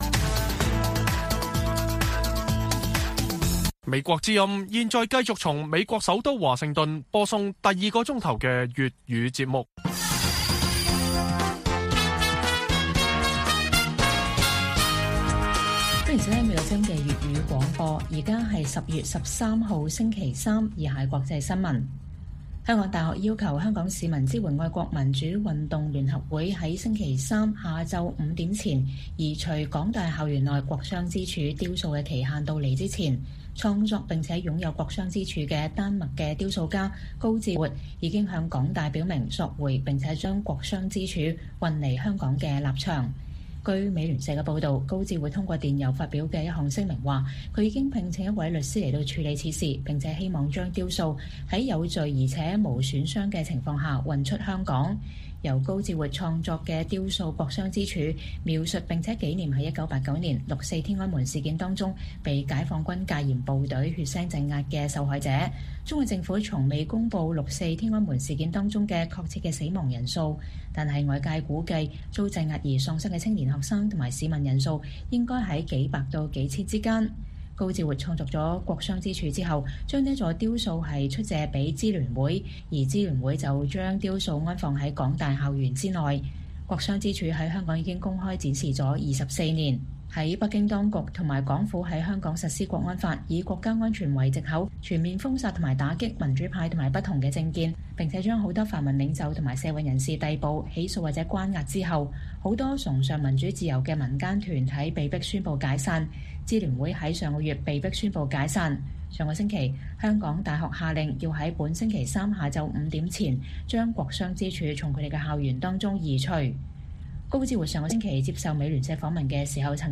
粵語新聞 晚上10-11點: “國殤之柱”創作人尋求索回該藝術品